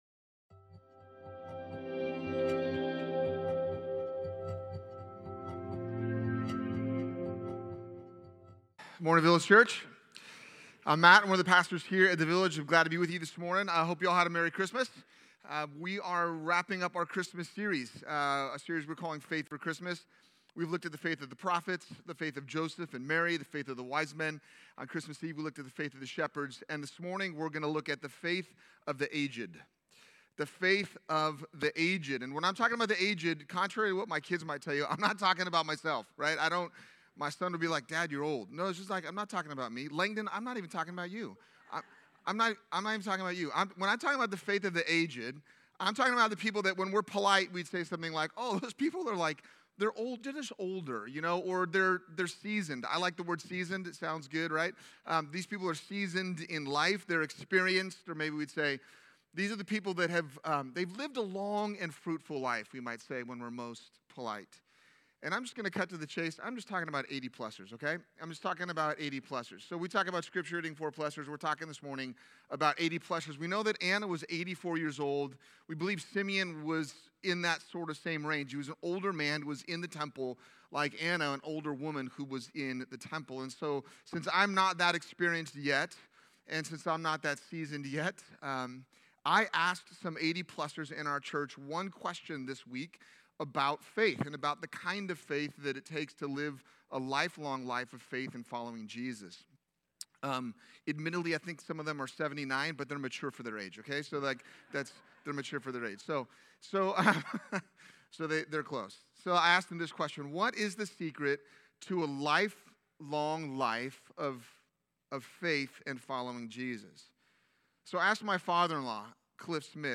Sunday morning sermons from Village Church in Irvine, California.